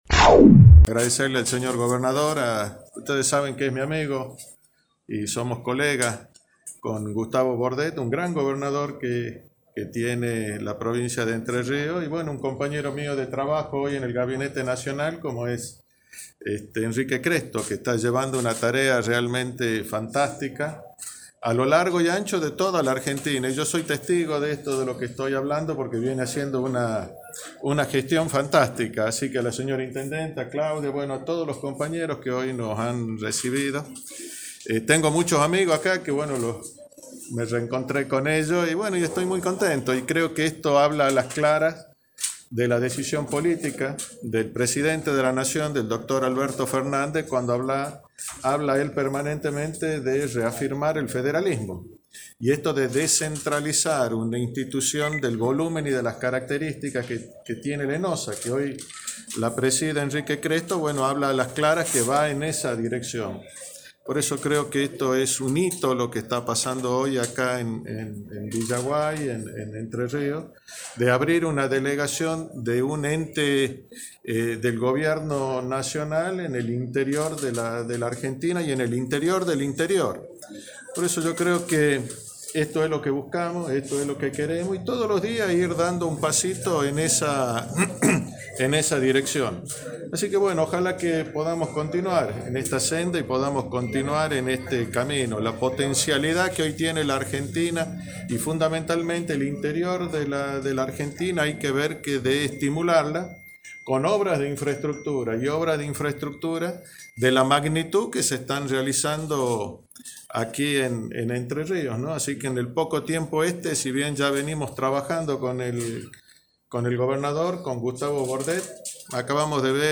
Con la presencia del Jefe de Gabinete de Ministros de la Nación Juan Manzur, el gobernador de Entre Ríos Gustavo Bordet, el titular del ENOHSA Enrique Cresto y autoridades de las provincias de Corrientes y Misiones, se inauguró en el mediodía de este jueves, la Delegación Mesopotámica del ENOHSA, en Villaguay.
Conferencia de Prensa
conferencia-de-prensa-enhosa-web.mp3